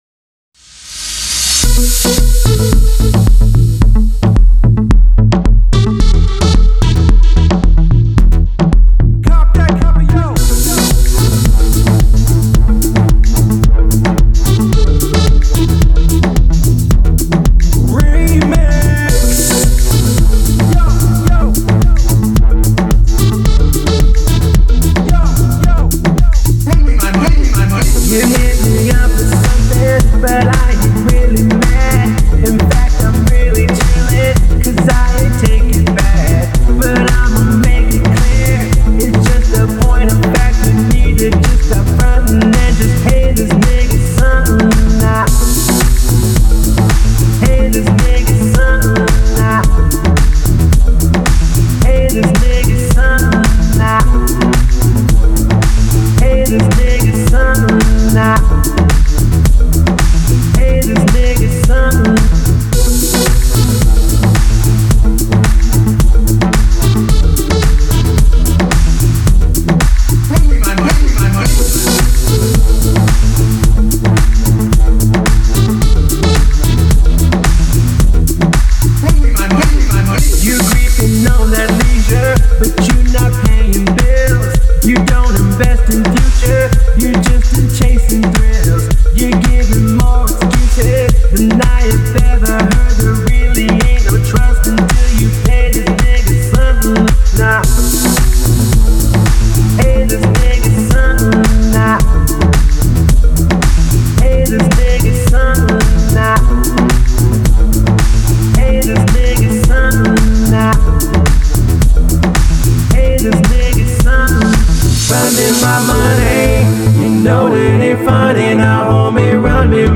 (Dance Club ReMix) Explicit